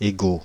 Ääntäminen
Synonyymit moi Ääntäminen France (Île-de-France): IPA: /e.ɡo/ Haettu sana löytyi näillä lähdekielillä: ranska Käännöksiä ei löytynyt valitulle kohdekielelle.